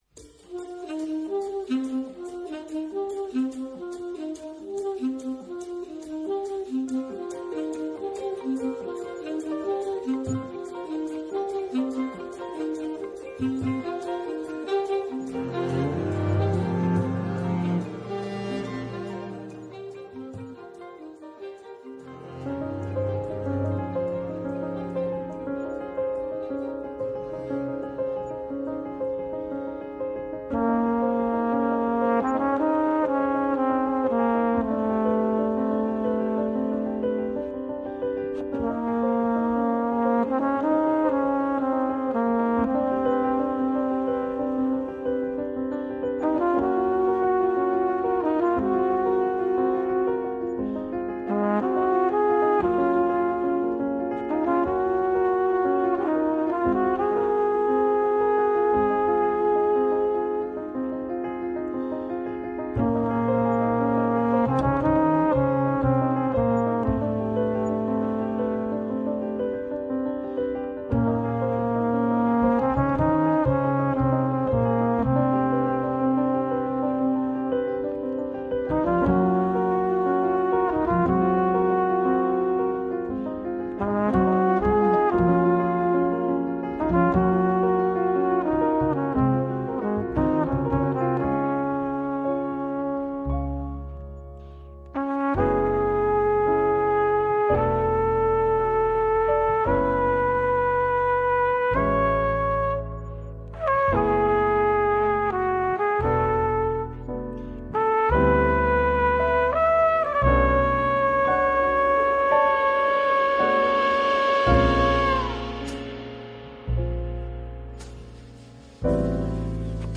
Centraal staat de promotie van jazz en beyond.